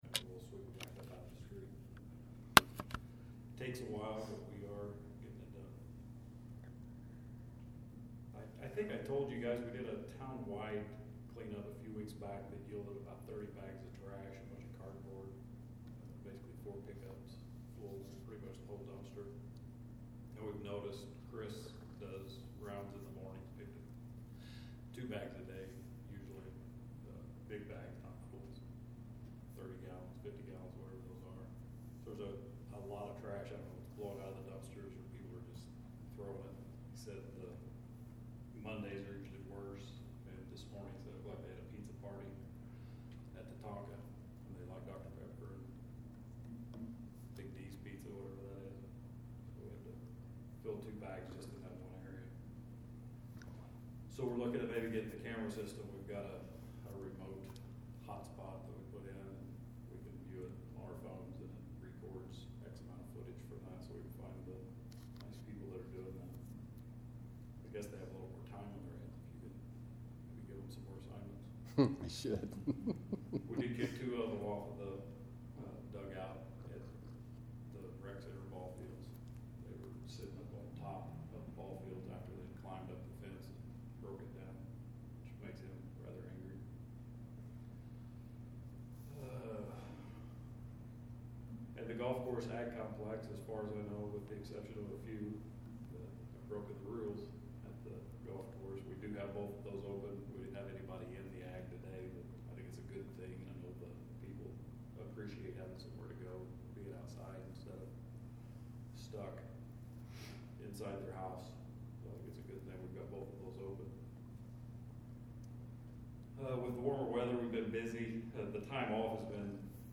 TOWN MEETINGS - 2020 ARCHIVED MINUTES